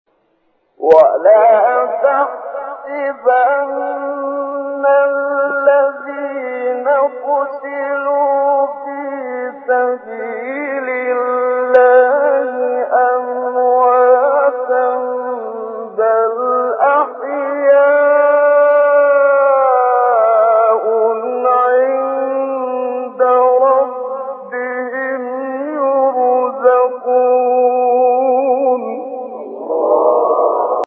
گروه شبکه اجتماعی: فرازهای صوتی از تلاوت قاریان برجسته مصری را می‌شنوید.
مقطعی از محمد صدیق منشاوی/ سوره ال عمران در مقام بیات